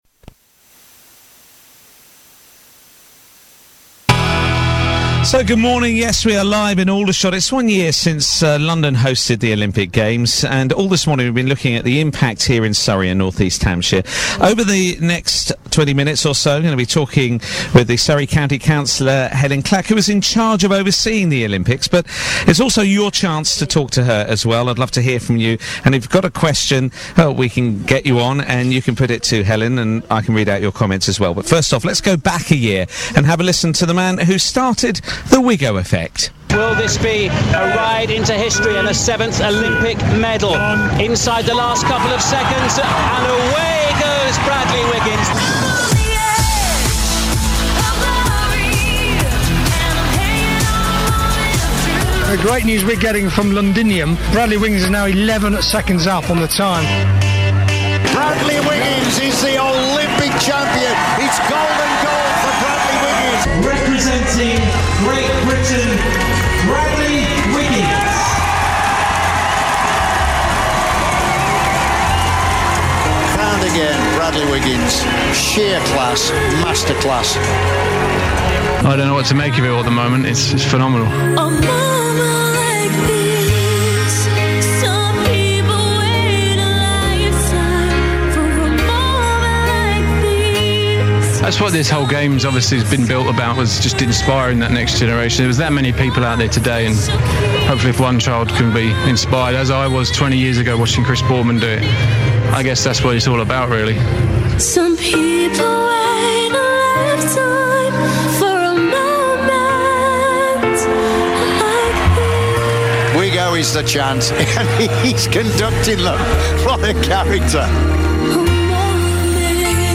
BBC Surrey interview Helyn Clack a year on from Olympics
Surrey County Council Cabinet Member for Community Services Helyn Clack has been interviewed by BBC Surrey about the success of the 2012 Games one year on from the Olympic cycle races on the county’s roads. During the interview she talks about the economic benefits the Games brought and looks ahead to this weekend’s Prudential RideLondon events in Surrey for 20,000 amateurs and the world’s best cyclists.